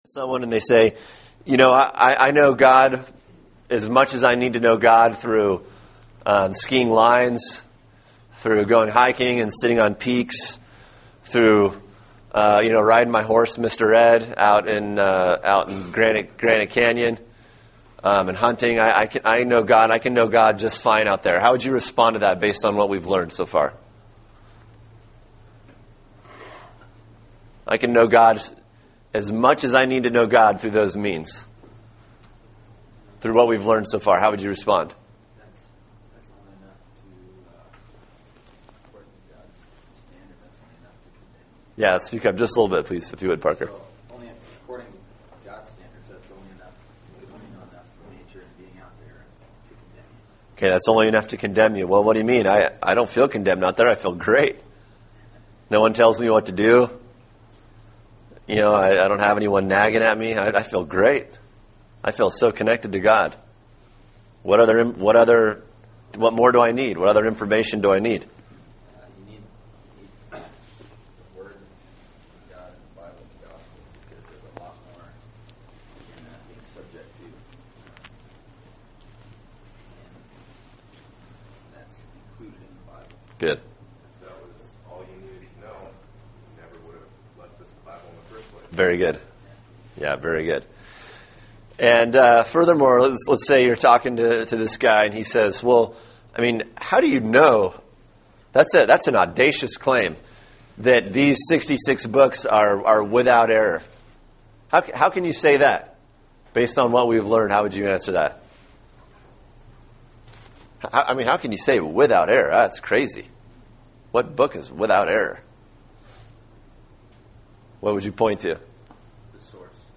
Words of Life: Bibliology Lesson 6: The Sufficiency of Scripture (Is the Bible enough for life and godliness?), and its Illumination (How does the Bible change us?)